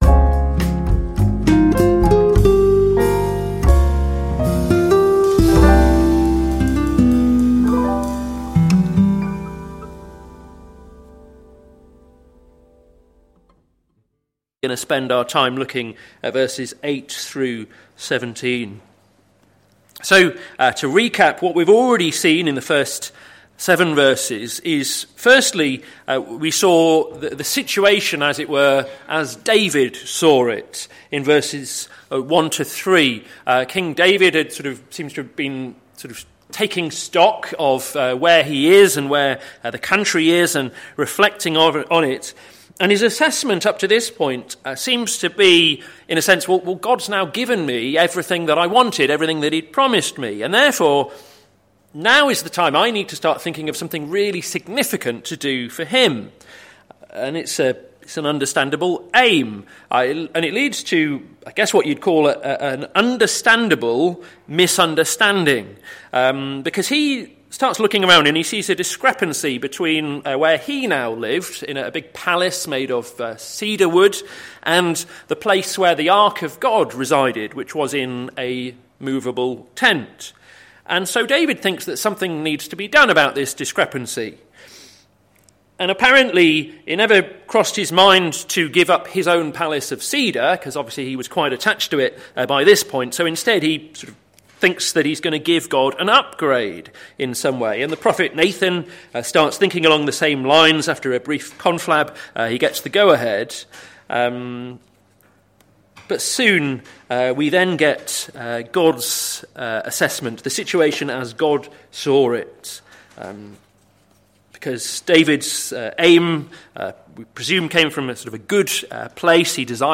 Sermon Series - May the Lord establish His Word - plfc (Pound Lane Free Church, Isleham, Cambridgeshire)